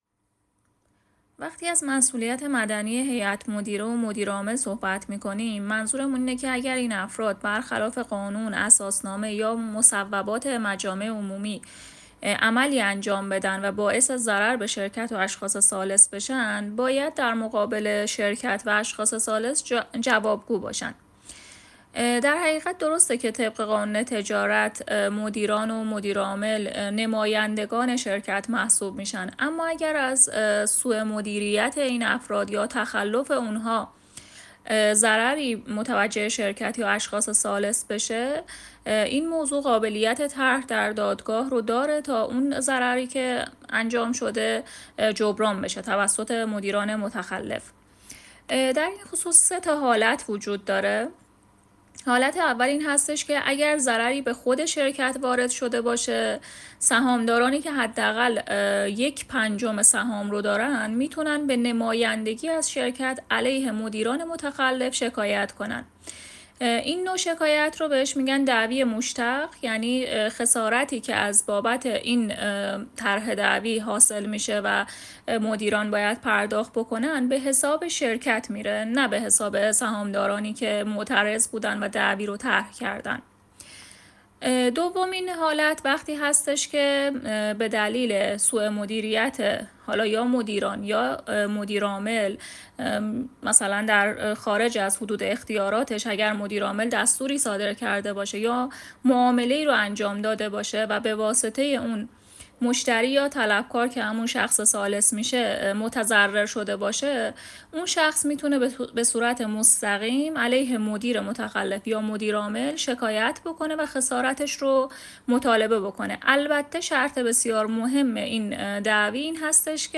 ویس پاسخ به سوال: